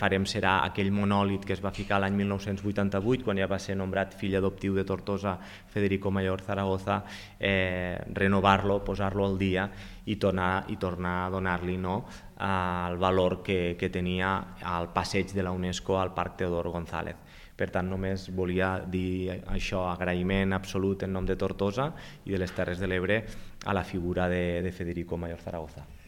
L’Alcalde de Tortosa, Jordi Jordan, ha agraït a la figura de Mayor Zaragoza per la seva implicació amb la ciutat i ha anunciat que l’Ajuntament renovarà el monòlit situat al parc Teodor Gonzàlez erigit quan es va nomenar l’exdirector general de la Unesco, fill adoptiu de Tortosa l’any 1988…